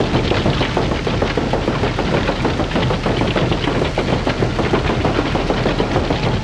big-mining-drill-moving-loop.ogg